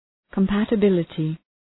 Προφορά
{kəm,pætə’bılətı}